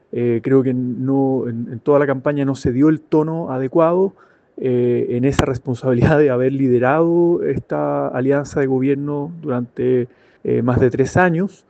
En tanto, el senador Juan Ignacio Latorre sostuvo que el Frente Amplio también debería entrar a analizar cómo ser una fuerza emergente en la izquierda y, al mismo tiempo, tener la responsabilidad de gobernar.